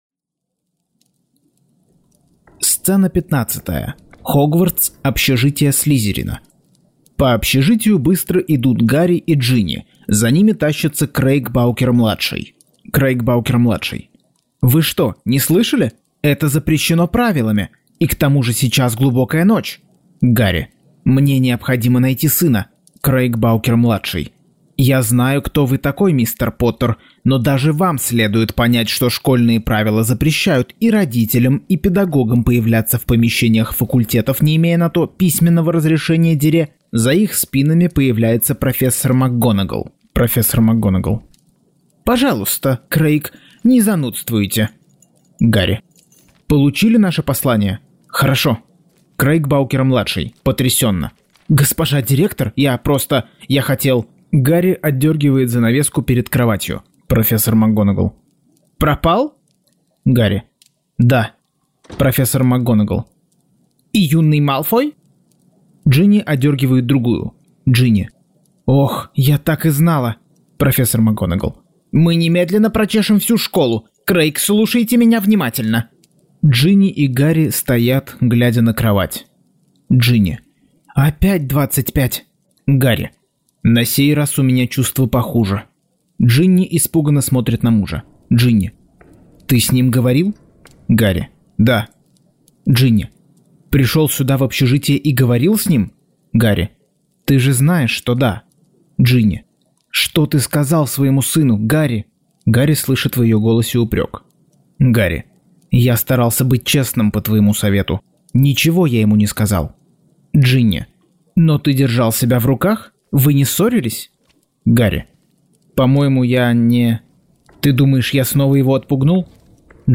Аудиокнига Гарри Поттер и проклятое дитя. Часть 47.